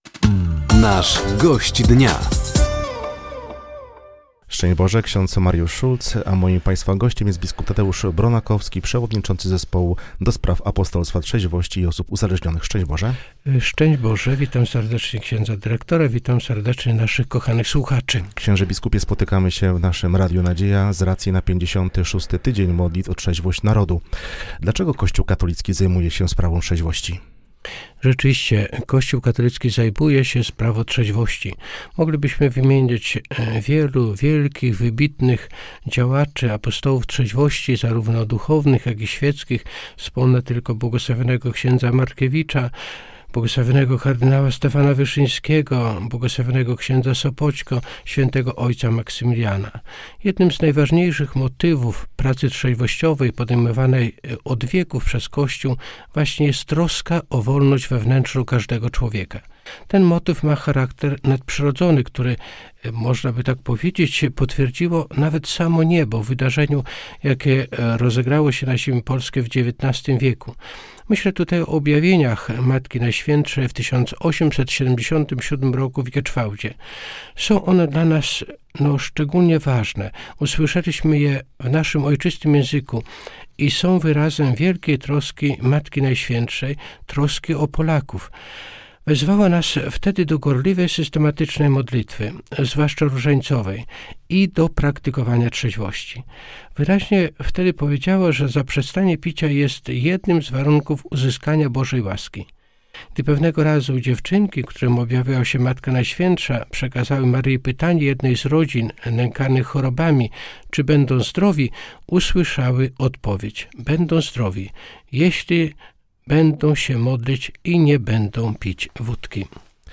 Gościem Dnia Radia Nadzieja był dzisiaj (22.02) bp Tadeusz Bronakowski, przewodniczący Zespołu Konferencji Episkopatu Polski ds. Apostolstwa Trzeźwości i Osób Uzależnionych. Tematem rozmowy był trwający 56. Tydzień Modlitw o Trzeźwość Narodu.